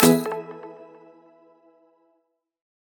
menu-char-click.ogg